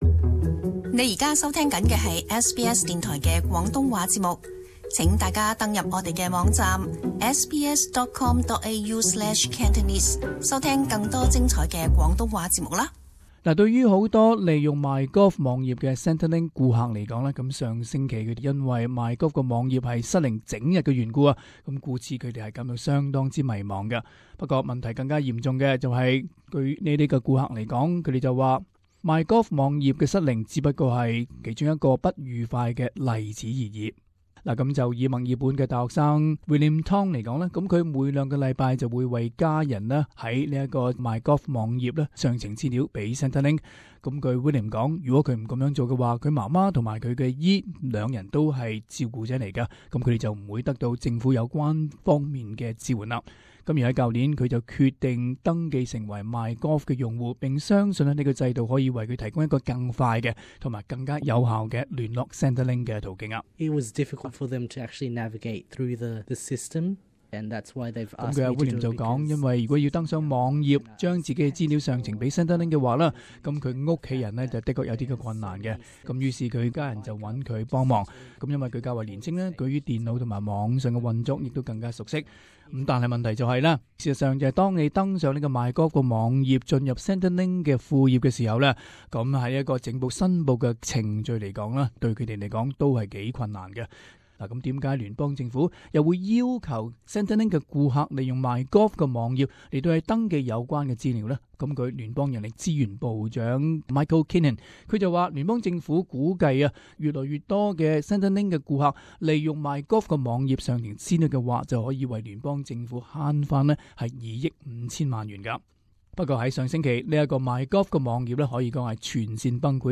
【時事報導】Centrelink 顧客不滿MyGov網頁失靈